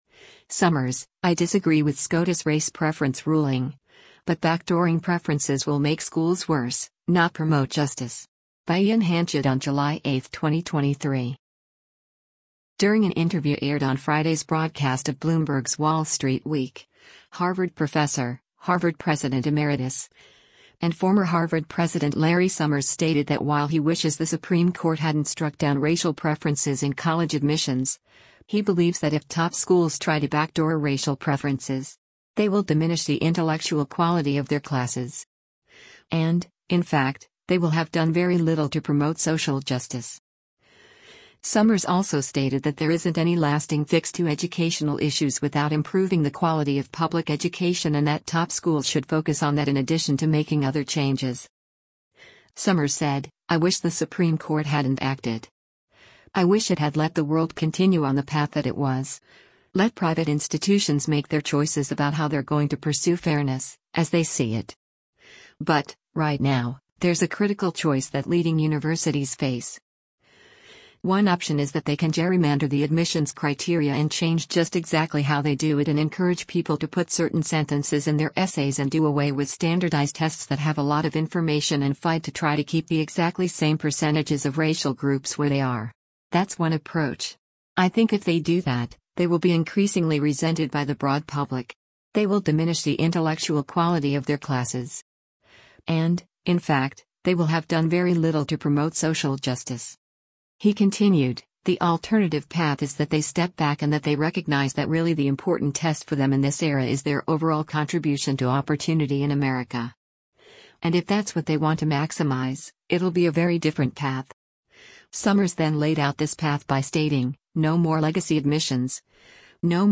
During an interview aired on Friday’s broadcast of Bloomberg’s “Wall Street Week,” Harvard Professor, Harvard President Emeritus, and former Harvard President Larry Summers stated that while he wishes the Supreme Court hadn’t struck down racial preferences in college admissions, he believes that if top schools try to backdoor racial preferences.